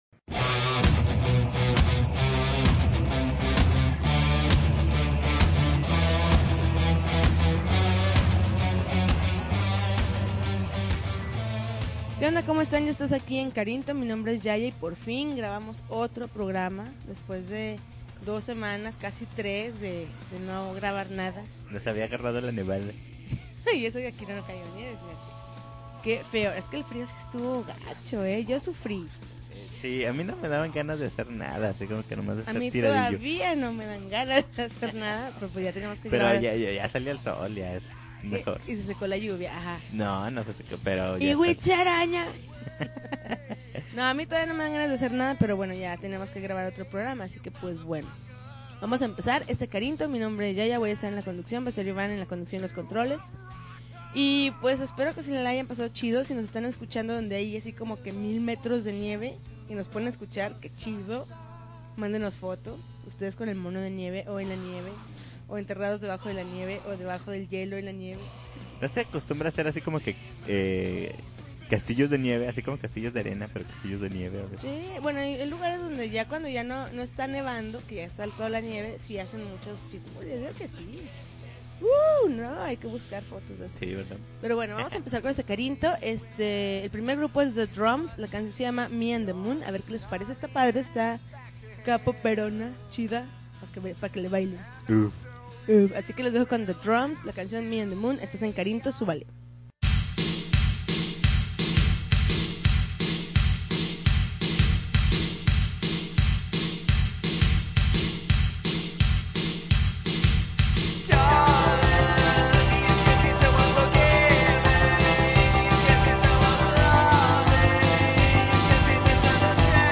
February 8, 2011Podcast, Punk Rock Alternativo